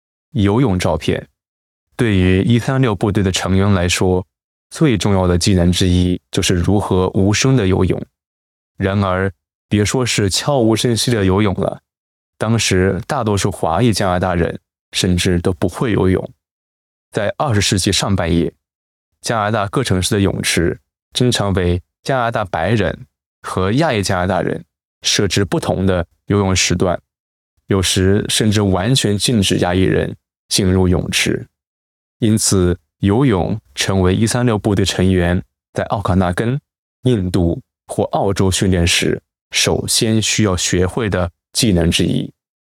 Special Operations Voiceovers
3_MAND_Swimming_Photo_voiceover__eq_.mp3